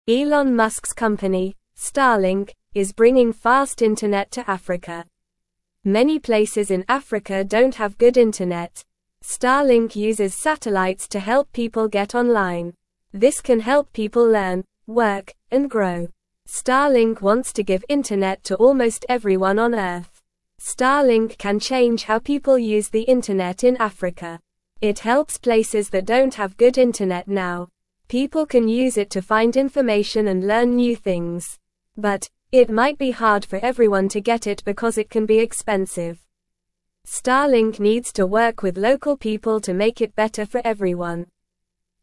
Normal
English-Newsroom-Beginner-NORMAL-Reading-Starlink-brings-fast-internet-to-Africa-to-help-people.mp3